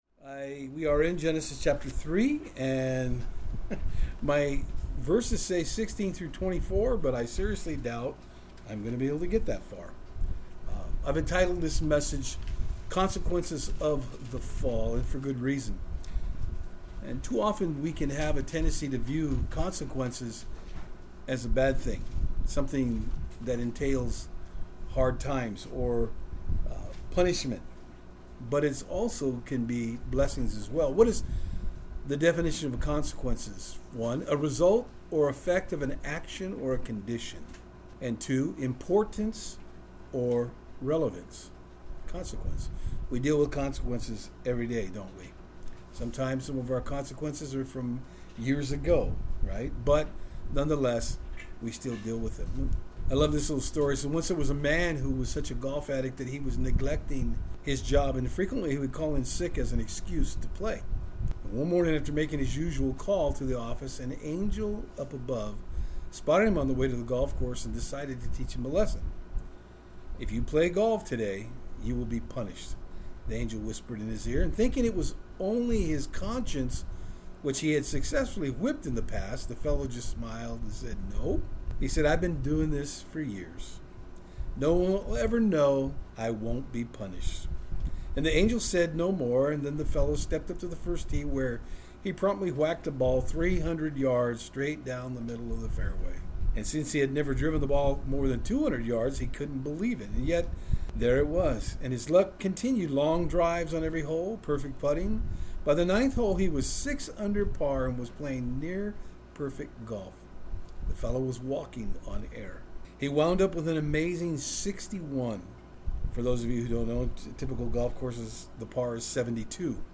Genesis 3:16 Service Type: Saturdays on Fort Hill In our study today we look at the consequence of the woman.